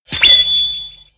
ANIME - SPARKLE 03
Category: Sound FX   Right: Commercial